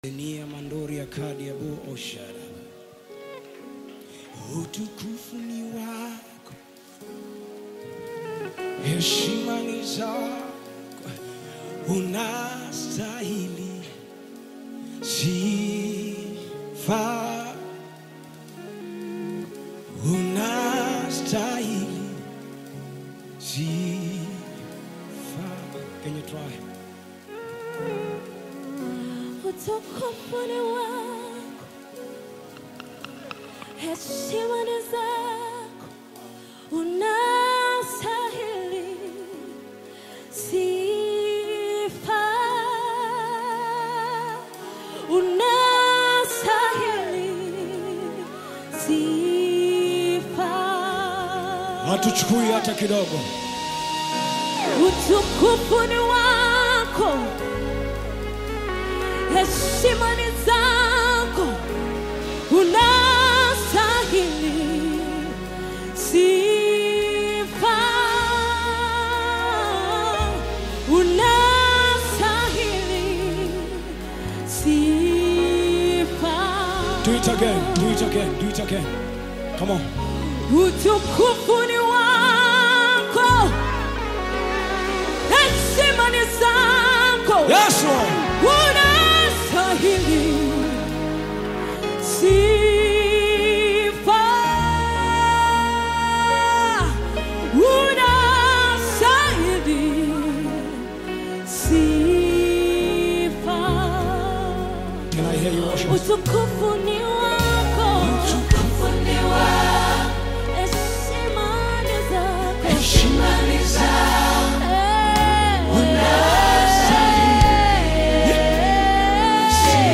Nyimbo za Dini music
Gospel music track
Tanzanian gospel artists